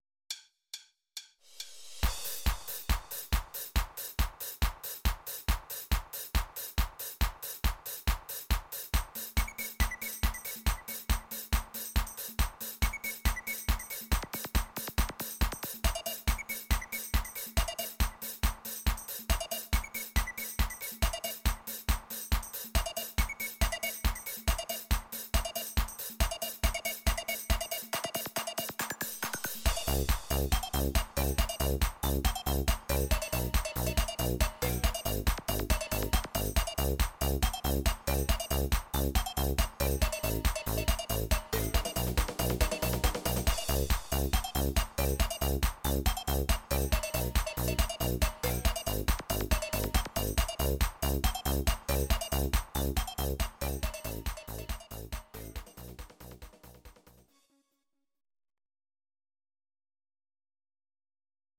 Demo/Koop midifile
Genre: Dance / Techno / HipHop / Jump
Demo's zijn eigen opnames van onze digitale arrangementen.